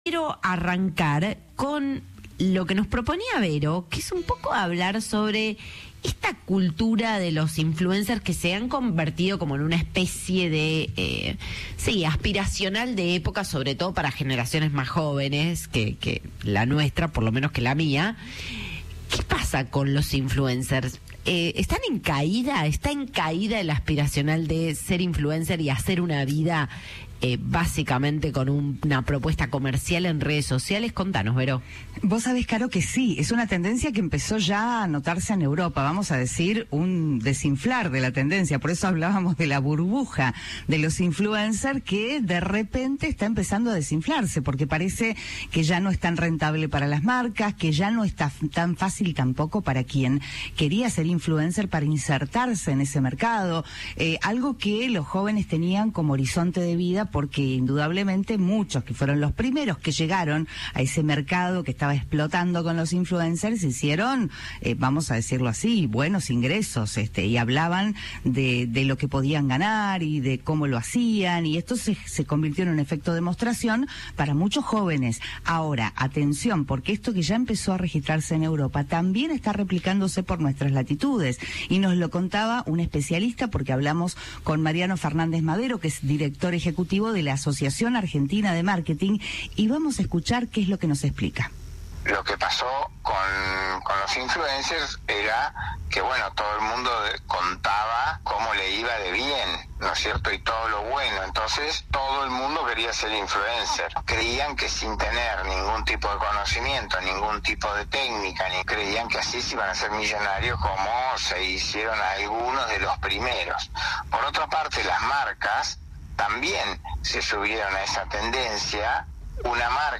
En diálogo con Cadena 3, el especialista explicó que el crecimiento inicial del fenómeno generó la percepción de que cualquier persona podía alcanzar el éxito económico sin formación ni conocimientos técnicos.